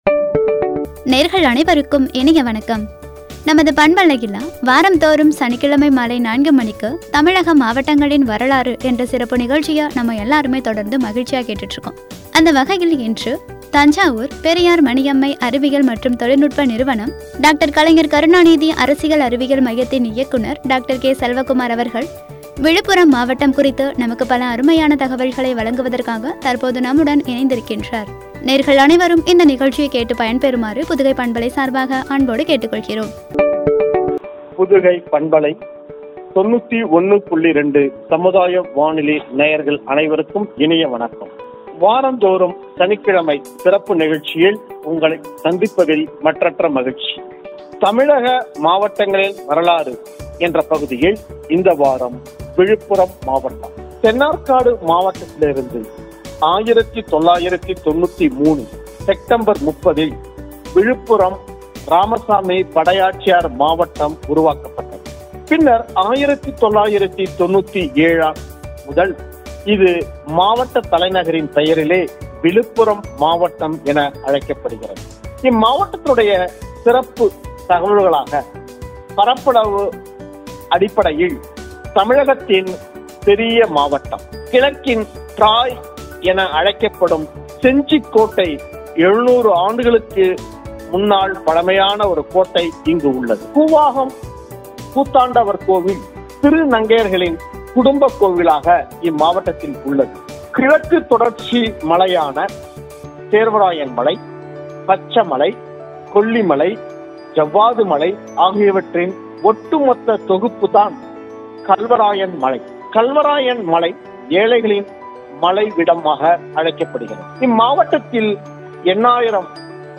உரை.